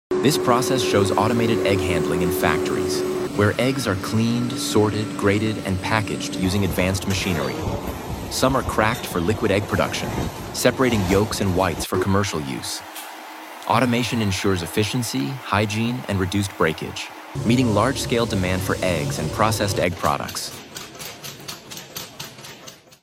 Automated machines clean, sort, grade,